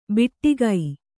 ♪ biṭṭigai